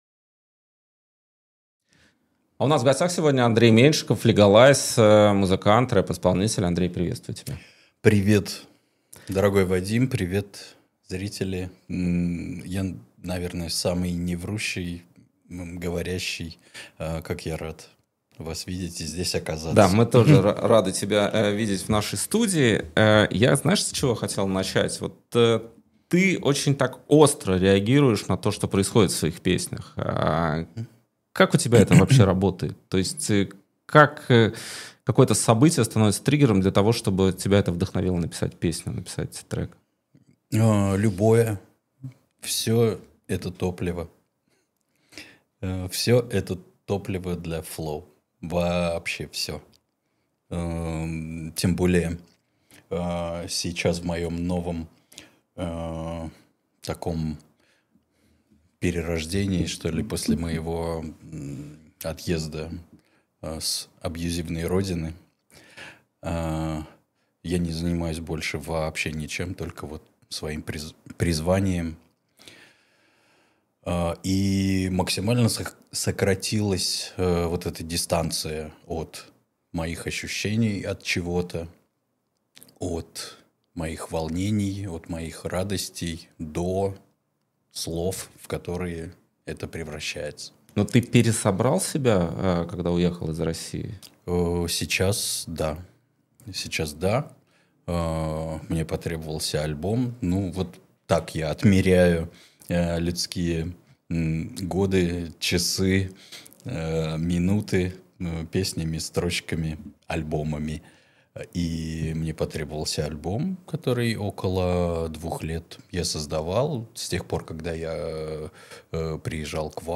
Эфир ведёт Вадим Радионов